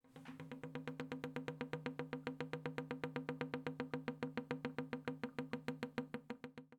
bathroom-sink-17
bath bathroom bubble burp click drain dribble dripping sound effect free sound royalty free Sound Effects